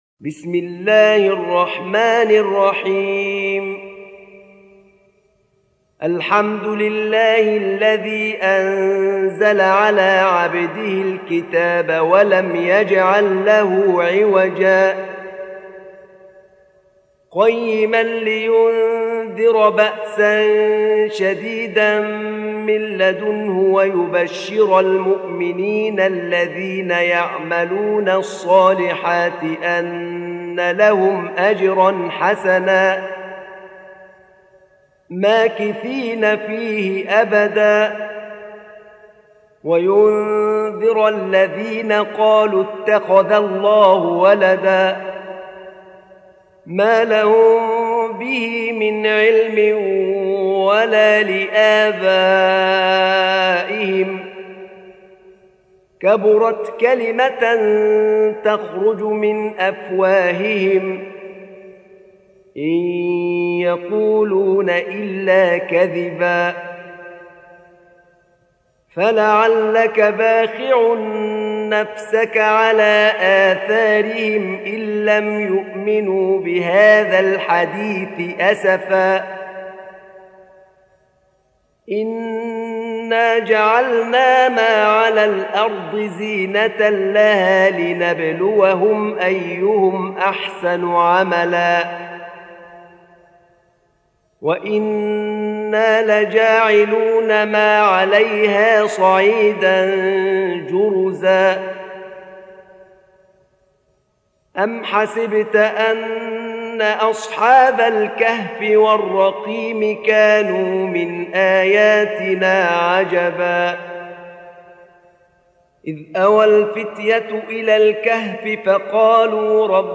سورة الكهف | القارئ أحمد نعينع